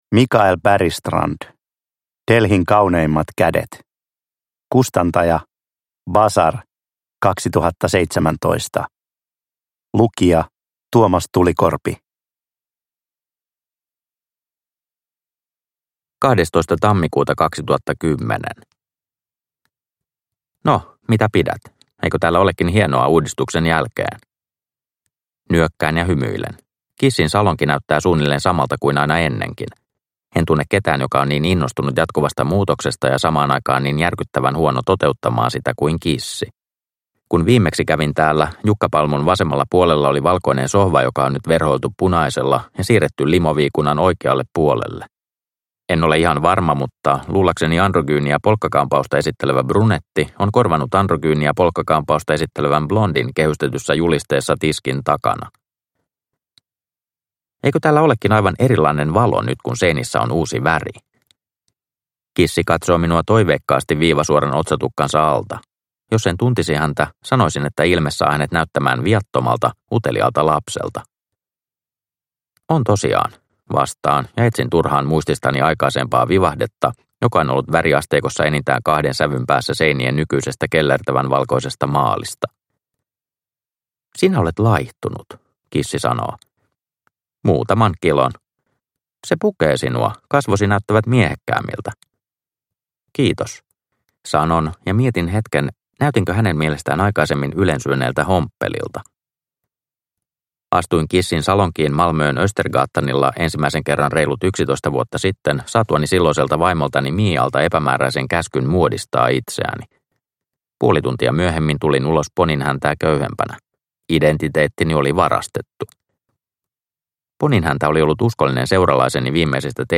Delhin kauneimmat kädet – Ljudbok – Laddas ner